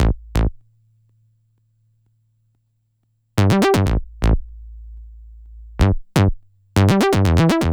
TI124BASS2-R.wav